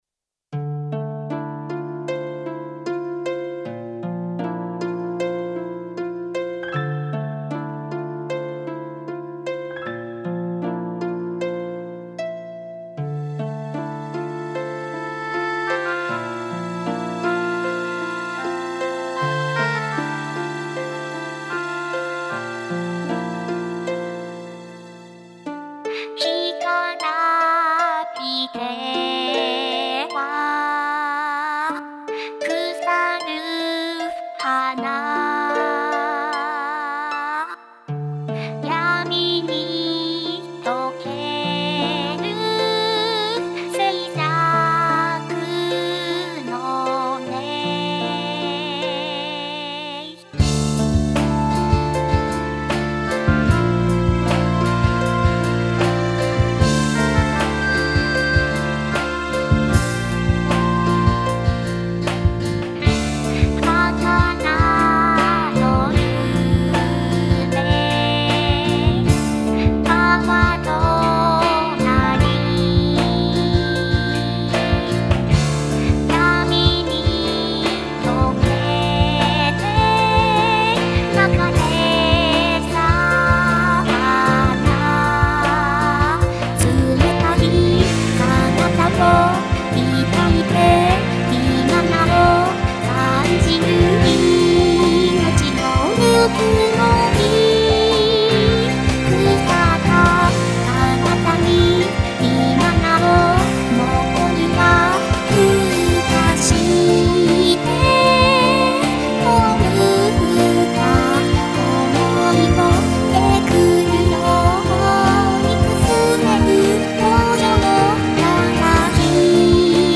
「葬送」をテーマにしたロックバラード曲です。
もの悲しい雰囲気が特徴です。